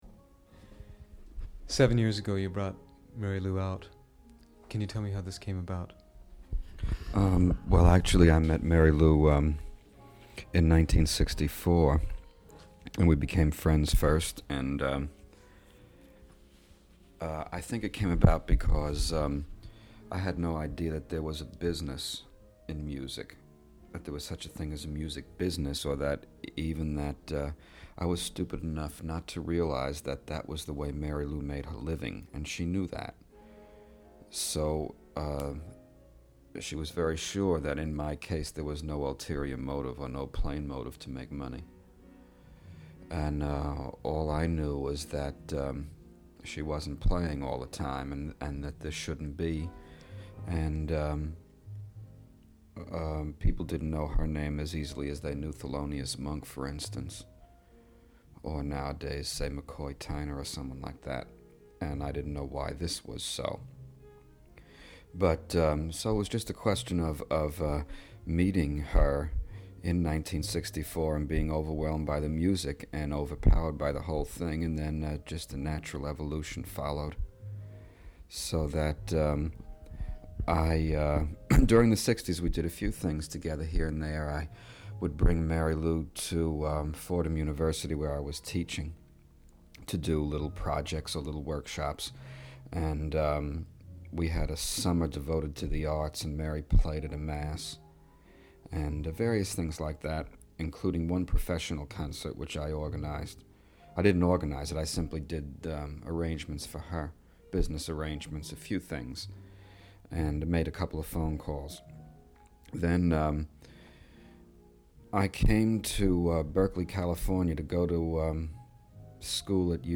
Interviews | Project Jazz Verbatim